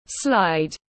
Cầu trượt tiếng anh gọi là slide, phiên âm tiếng anh đọc là /slaɪd/
Slide /slaɪd/